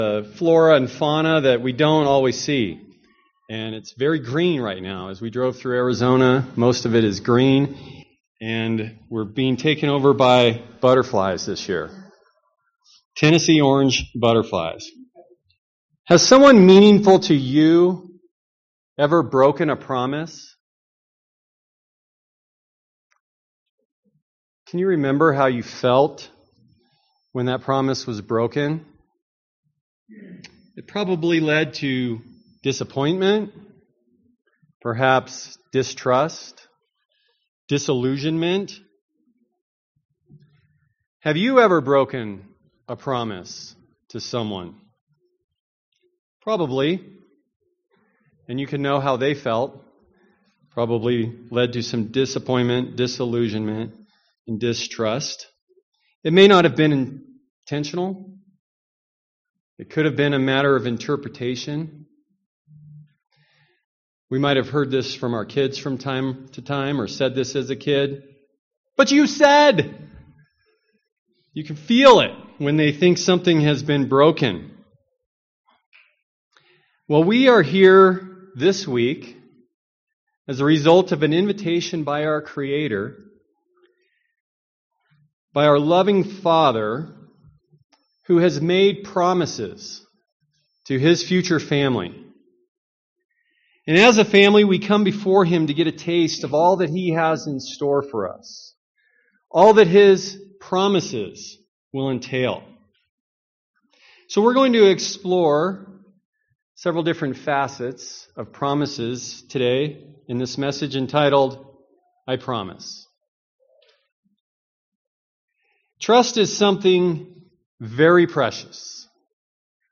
This sermon was given at the Steamboat Springs, Colorado 2021 Feast site.